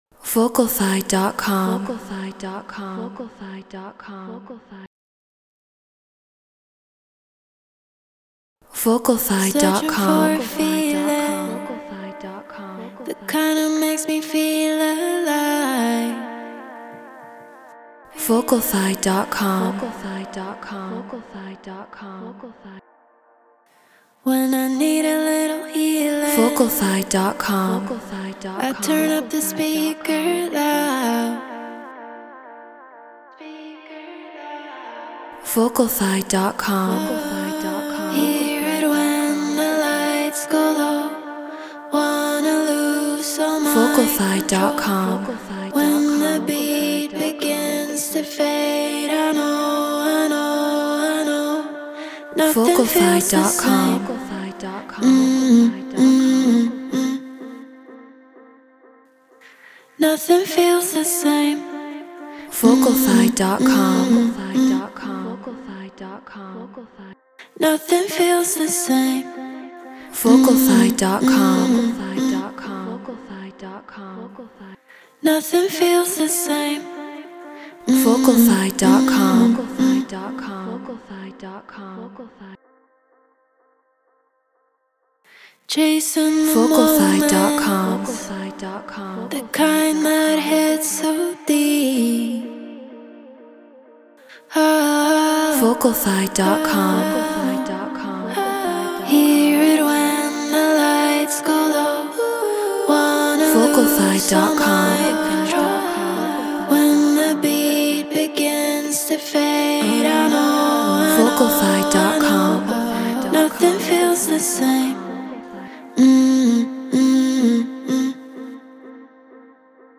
House 126 BPM Cmin
Human-Made